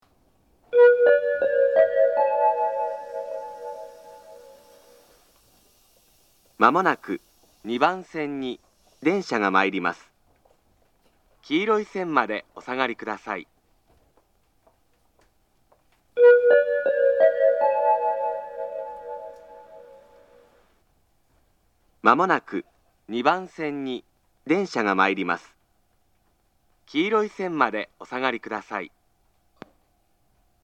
仙石旧型（男性）
接近放送
仙石旧型男性の接近放送です。同じ内容を2度繰り返します。
鳴動は遅めです。